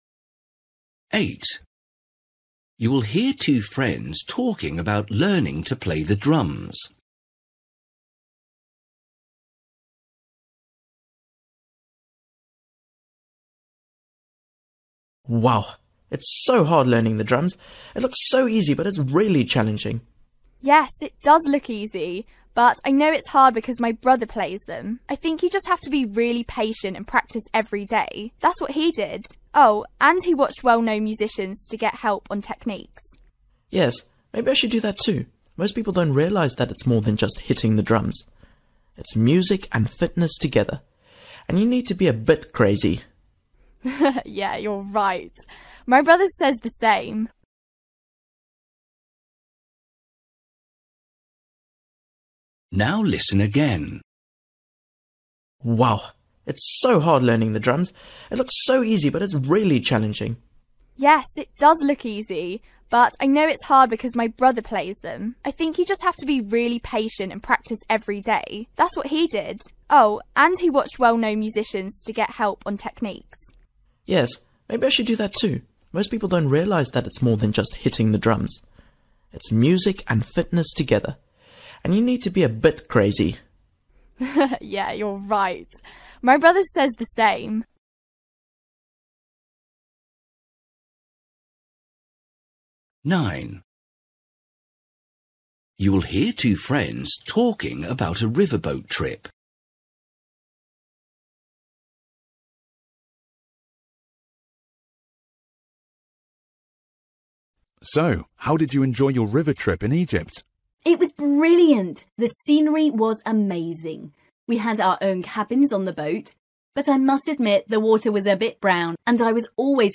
Listening: everyday short conversations
8   You will hear two friends talking about learning to play the drums. They agree that playing drums
9   You will hear two friends talking about a river boat trip. What did the boy like the least about it?